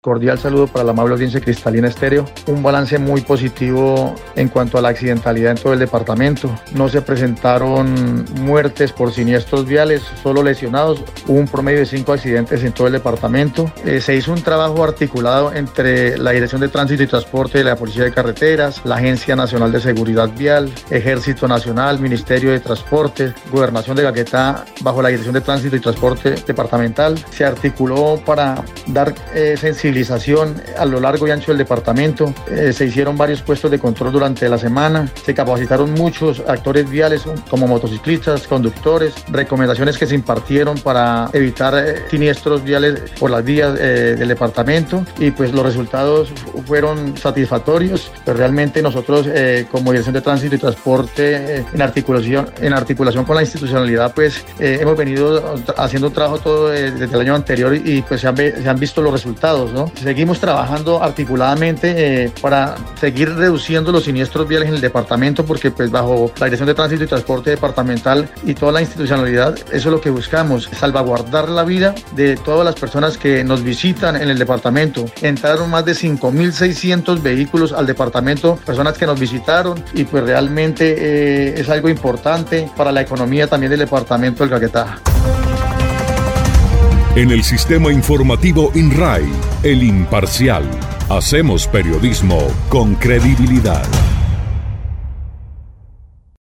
De acuerdo con Hernán Castañeda Suarez, director de tránsito departamental, el balance es positivo debido al trabajo articulado donde se realizaron varios puestos de control para sensibilizar a los diferentes actores viales y lograr disminuir los accidentes en carretera.